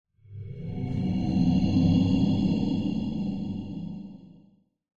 sounds / ambient / cave / cave8.mp3
cave8.mp3